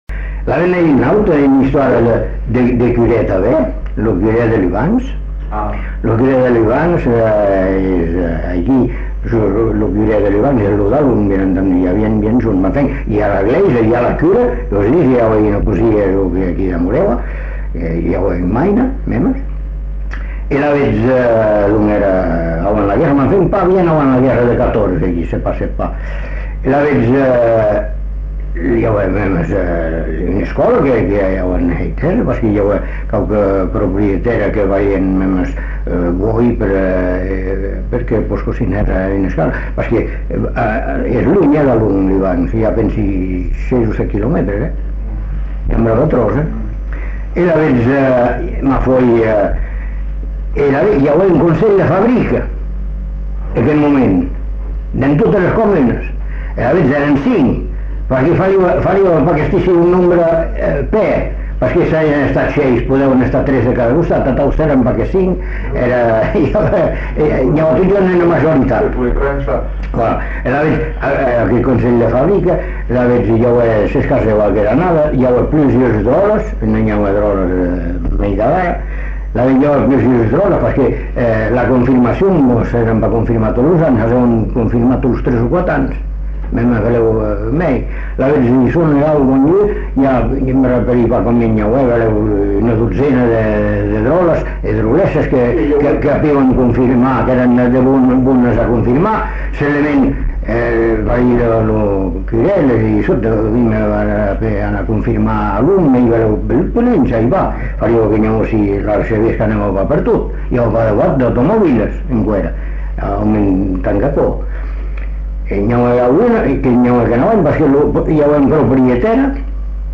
Genre : conte-légende-récit
Type de voix : voix d'homme Production du son : parlé
[enquêtes sonores]